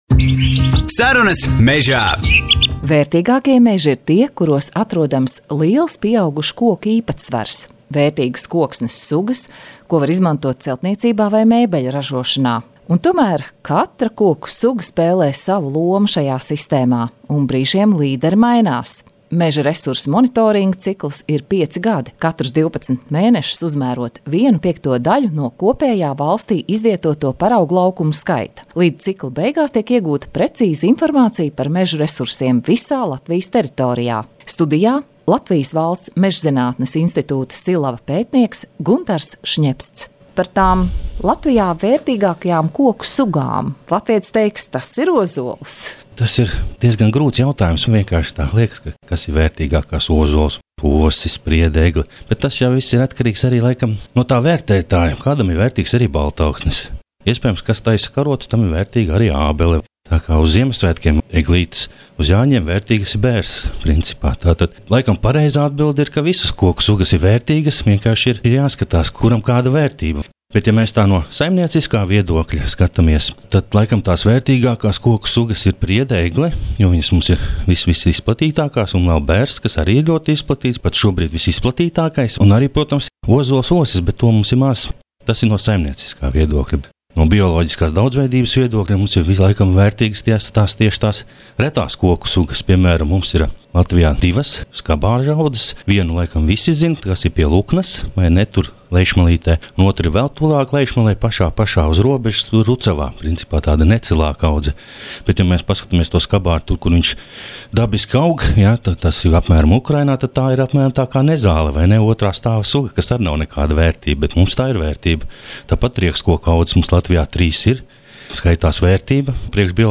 Intervijas un fakti- tas viss – „Sarunās mežā”.